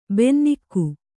♪ bennikku